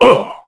Kaulah-Vox_Damage_kr_01.wav